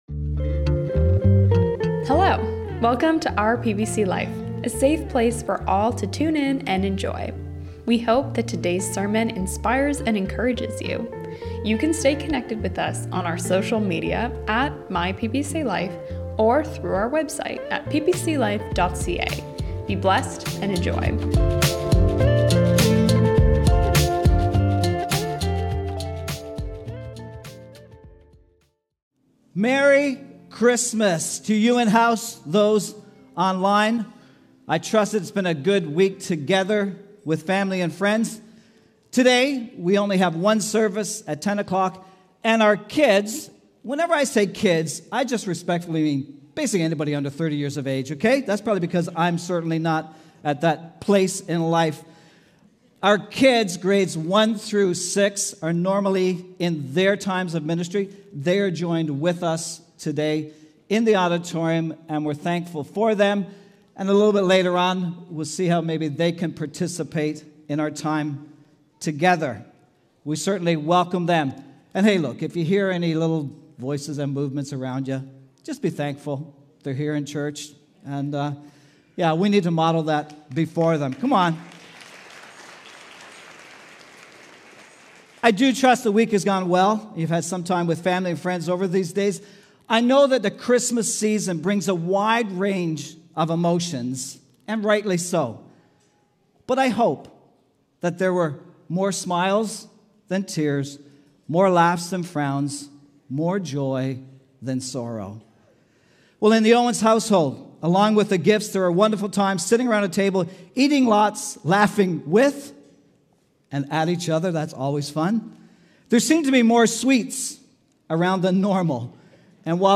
This is the final sermon of 2025!